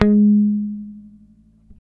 -JP PICK G#4.wav